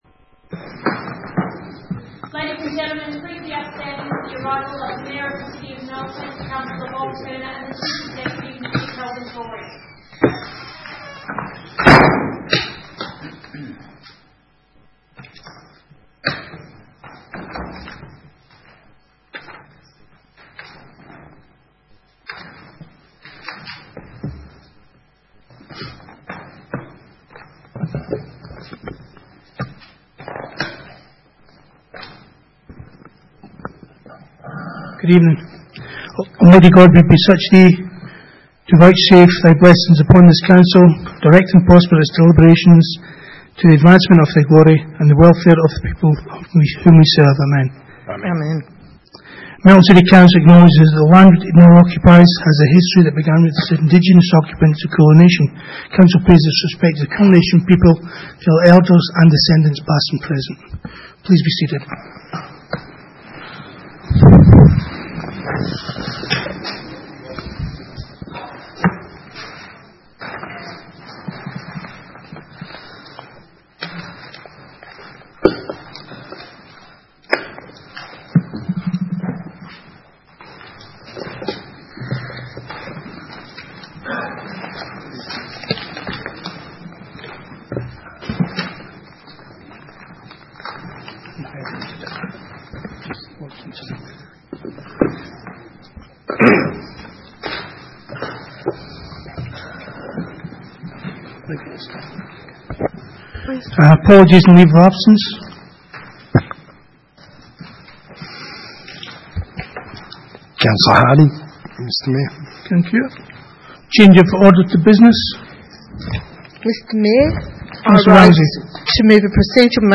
Ordinary Meeting - 5 February 2018